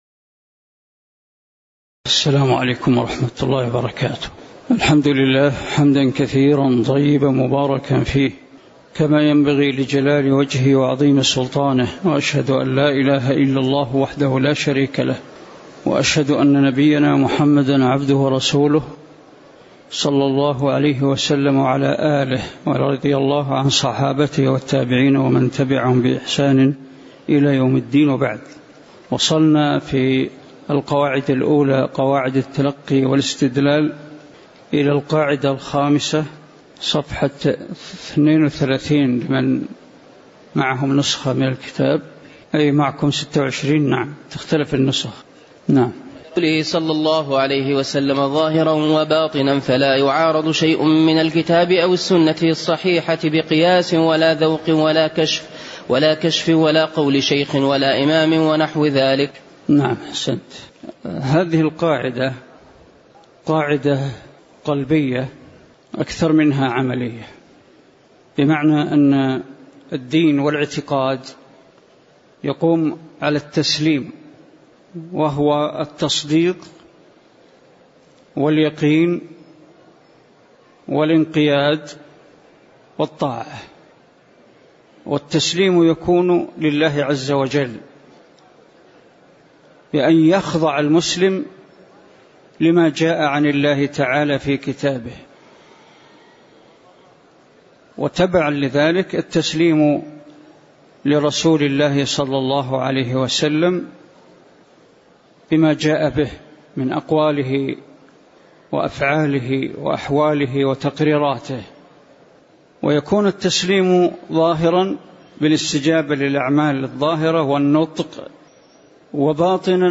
تاريخ النشر ٢٧ ربيع الثاني ١٤٣٩ هـ المكان: المسجد النبوي الشيخ: ناصر العقل ناصر العقل القاعدة الخامسة من قواعد التلّقي والإستدلال (002)